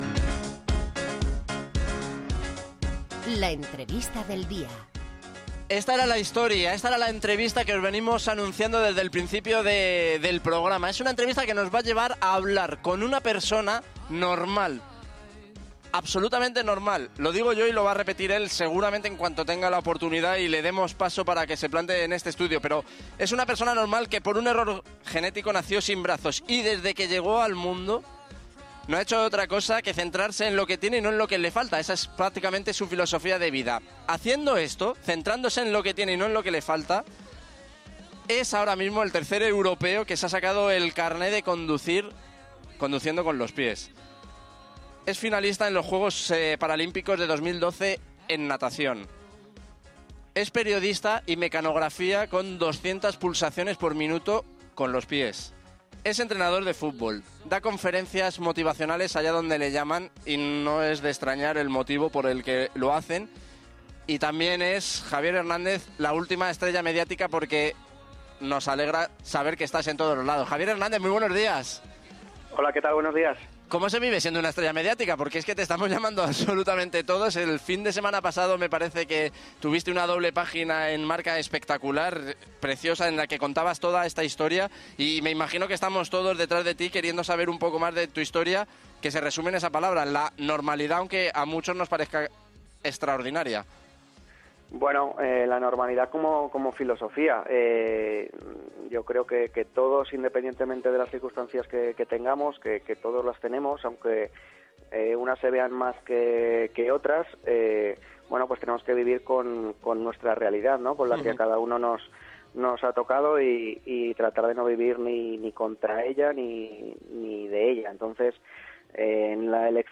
Como eco del reportaje, se sucedieron las entrevistas en radio a nivel nacional los días posteriores.